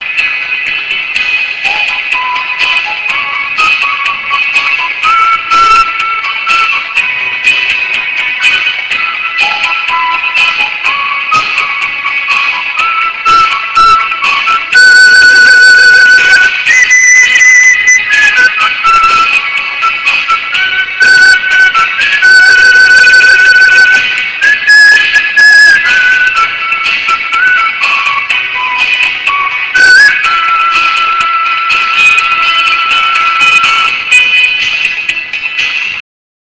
分﹕首句重複兩次﹐中句﹐末句又包括三個小重複。
歌詞以意境取勝﹐歌曲本身則往往平淡無奇。